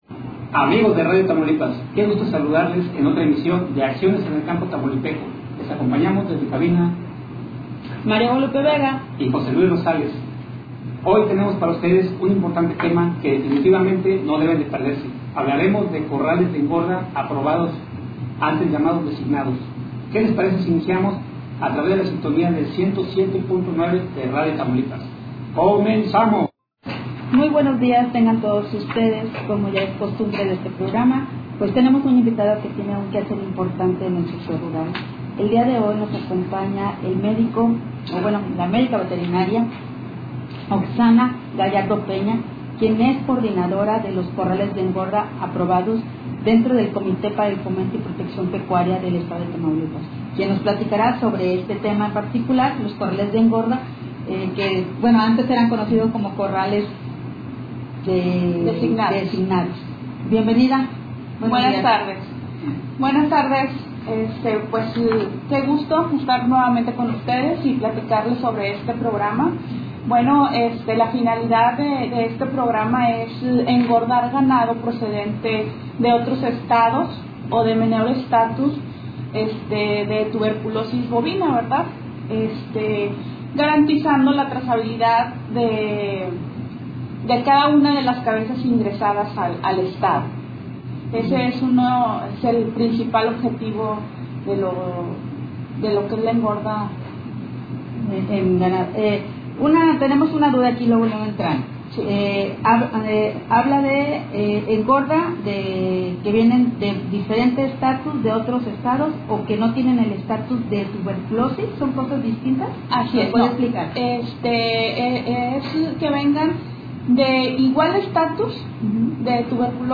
comenzó la entrevista platicando sobre el principal objetivo de un corral de engorda, así como cuantos corrales aprobados existen en el Estado, denotando al municipio de Jiménez por tener el mayor número.